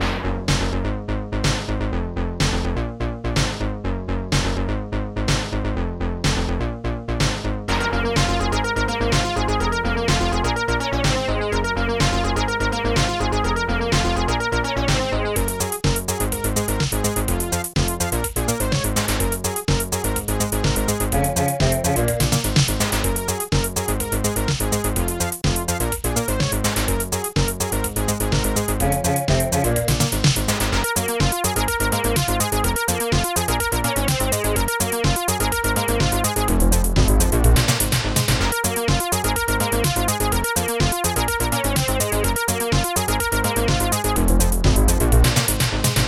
Digital Symphony Module
Instruments TUNEBASS POPSNARE2 blast KORGBEAU bassdrum3 HIHAT2 SHAKER synbrass HOOMAN MONSTERBAS